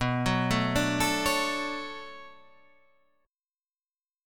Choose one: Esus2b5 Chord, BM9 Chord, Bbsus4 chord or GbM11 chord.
BM9 Chord